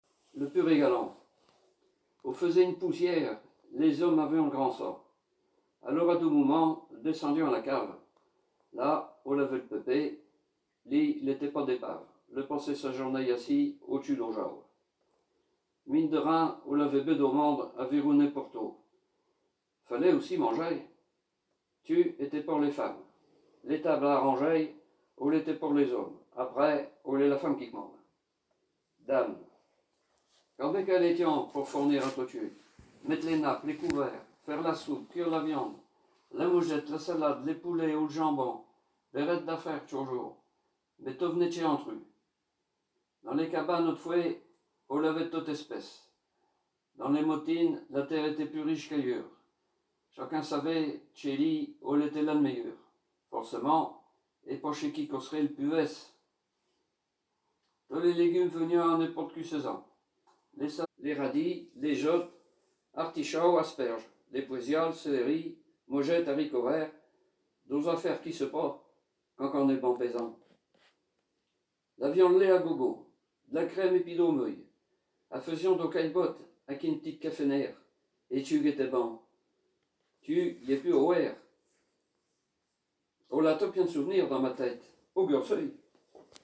Poésies en patois